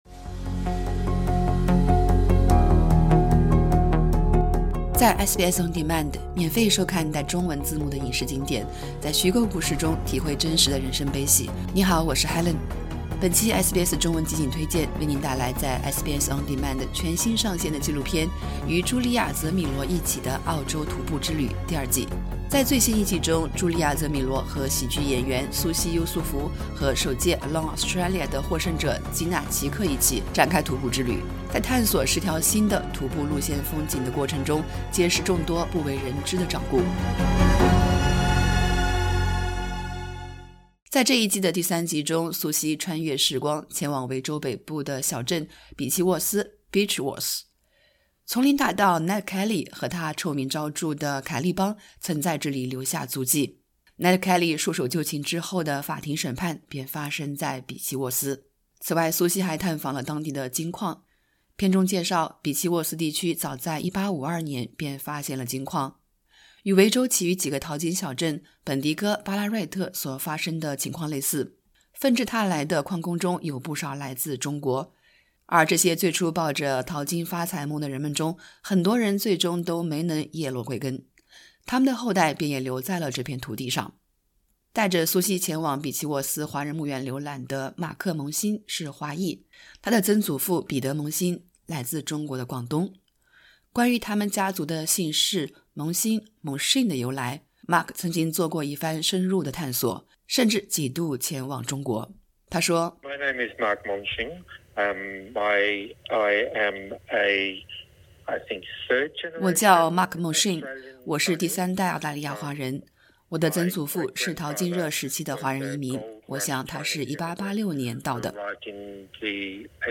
本期 《SBS中文集锦推荐》 为您带来在 SBS On Demand 全新上线的纪录片《与朱莉娅·泽米罗一起的澳洲徒步之旅》（Great Australian Walks with Julia Zemiro）第二季。 点击播放键收听完整采访